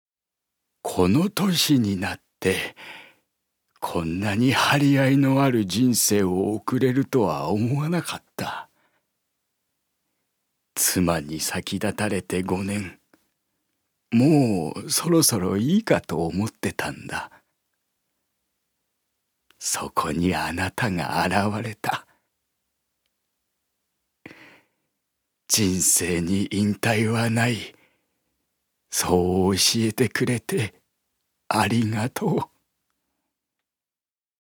所属：男性タレント
セリフ７